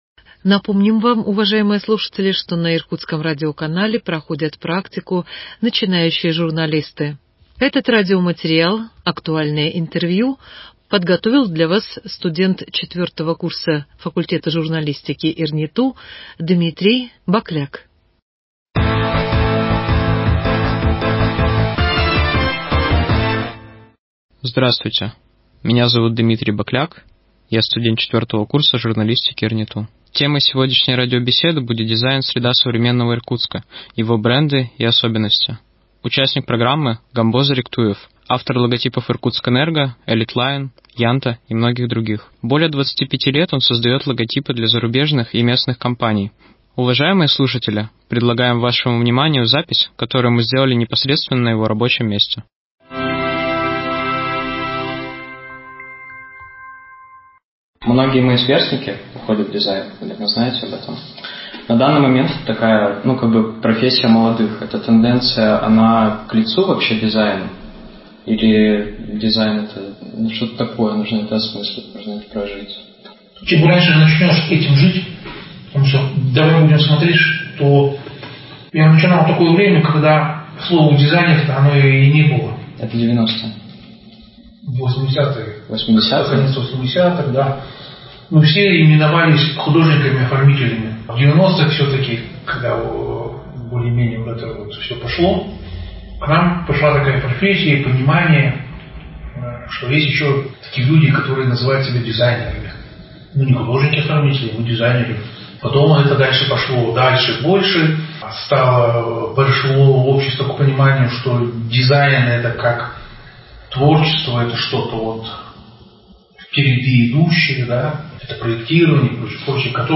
«Проба пера». Передача «Актуальное интервью».